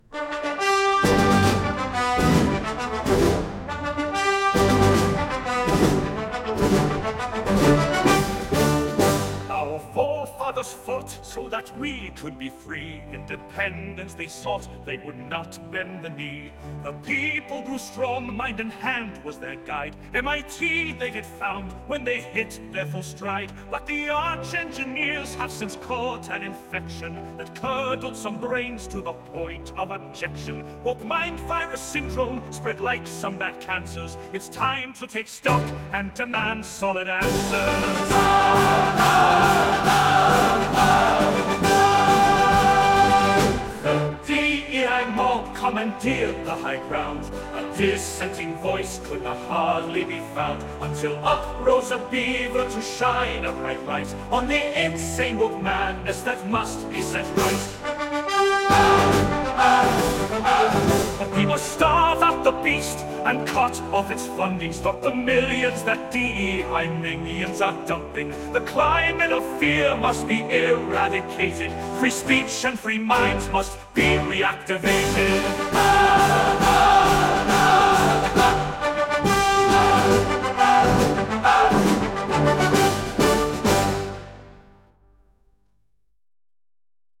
Independence Day Sing Along
Back by popular demand, the Suno empowered Crooning Castoridae Brothers offers a July 4th sing along to warm your cockles and stiffen your spine.
here as the miracle of generative AI brings our lyrics of righteous rebellion to life.